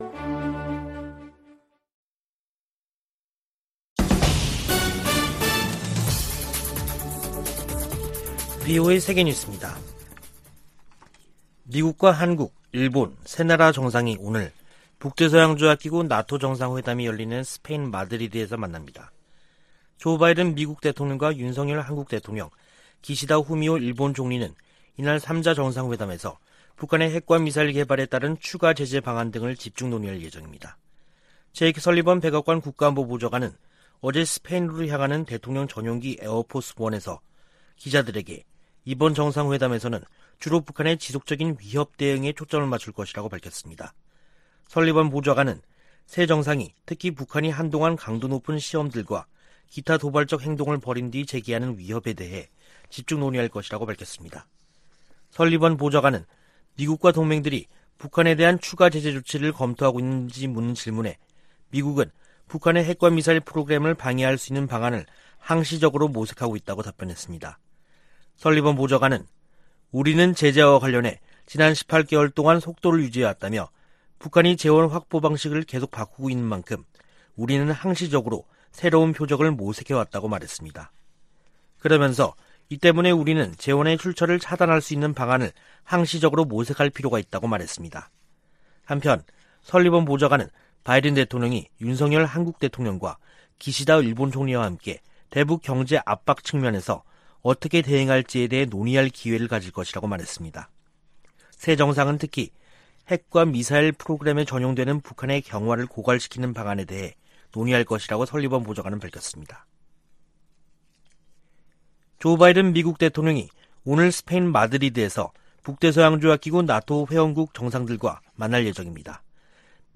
VOA 한국어 간판 뉴스 프로그램 '뉴스 투데이', 2022년 6월 29일 2부 방송입니다. 제이크 설리번 백악관 국가안보보좌관은 나토 정상회의 현장에서 열리는 미한일 정상회담에서 대북 경제 압박 방안이 논의될 것이라고 말했습니다. 옌스 스톨텐베르그 나토 사무총장은 새 전략개념을 제시하면서 중국과 러시아의 도전을 견제하겠다는 의지를 확인했습니다.